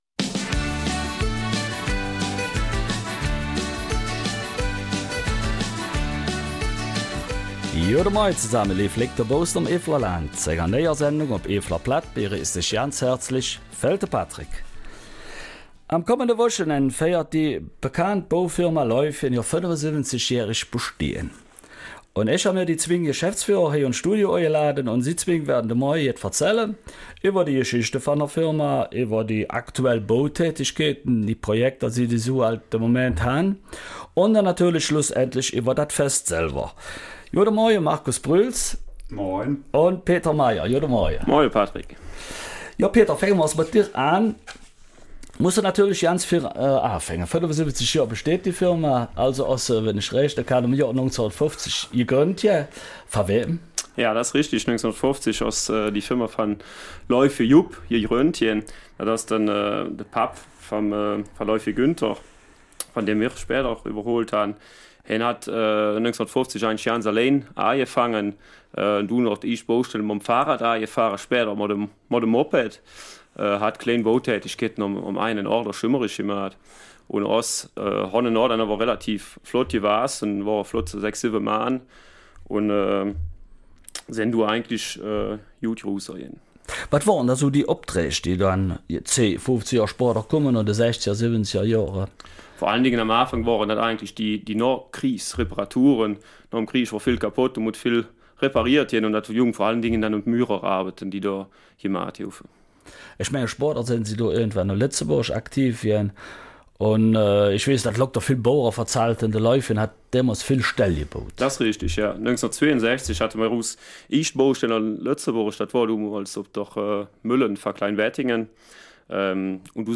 Eifeler Mundart: 75 Jahre Leufgen